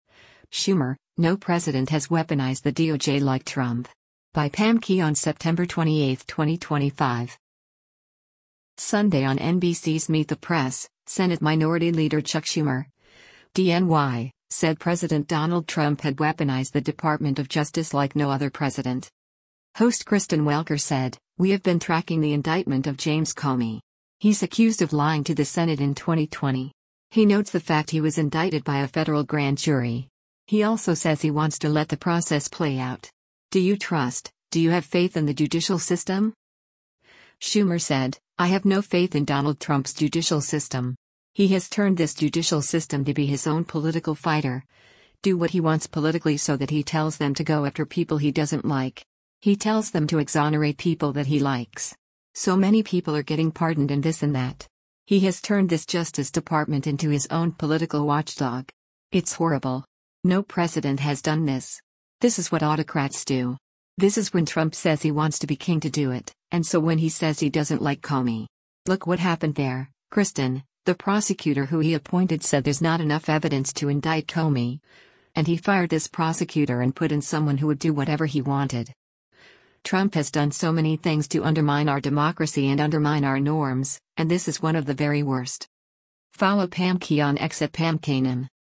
Sunday on NBC’s “Meet the Press,” Senate Minority Leader Chuck Schumer (D-NY) said President Donald Trump had weaponized the Department of Justice like no other president.